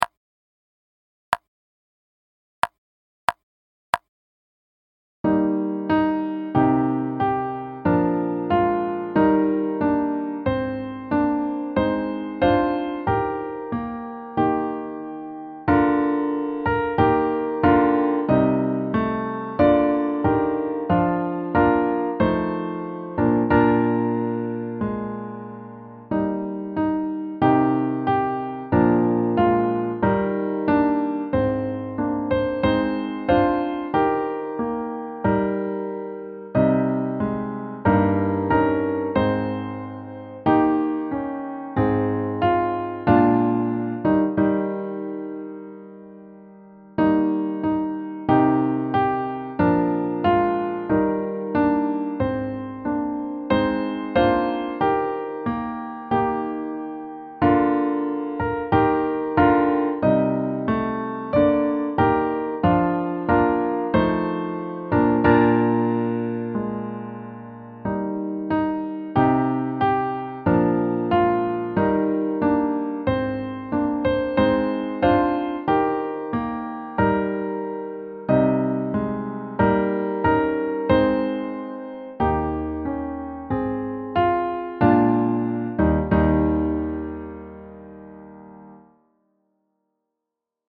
Accompaniment